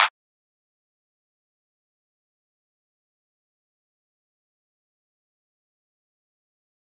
SCOTT_STORCH_clap_flute_song.wav